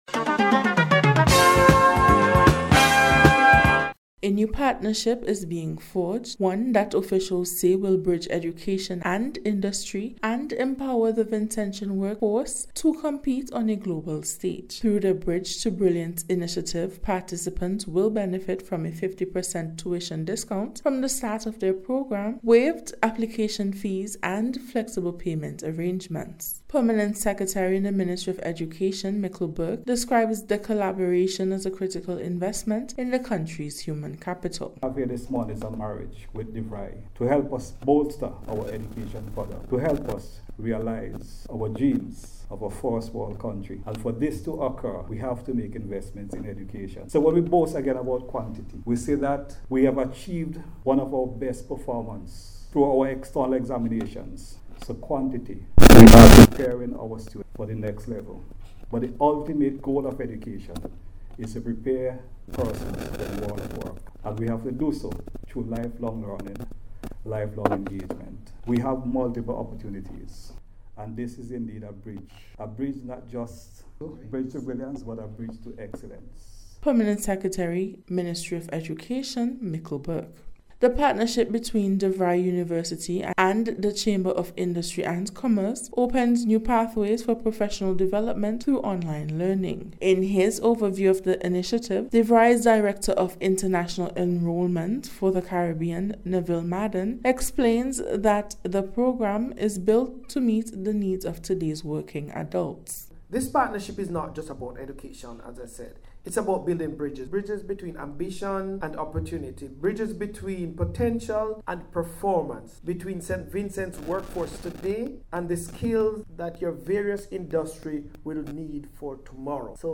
NBC’s Special Report- Thursday 30th October,2025